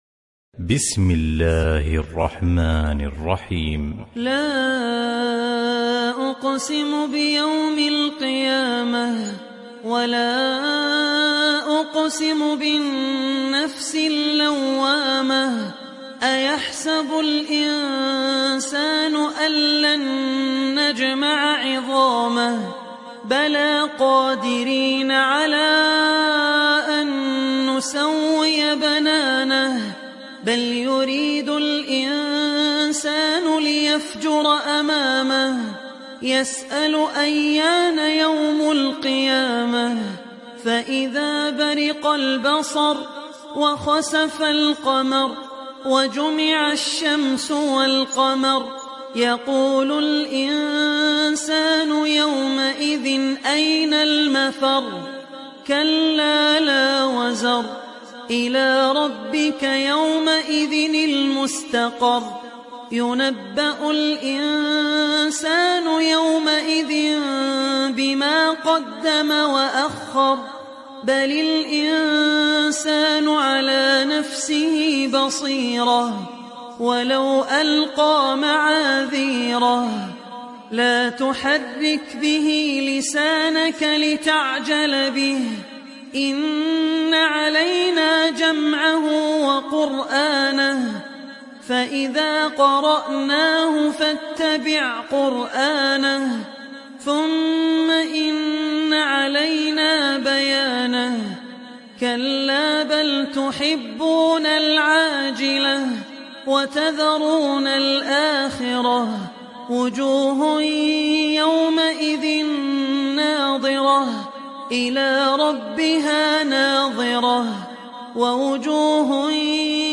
تحميل سورة القيامة mp3 بصوت عبد الرحمن العوسي برواية حفص عن عاصم, تحميل استماع القرآن الكريم على الجوال mp3 كاملا بروابط مباشرة وسريعة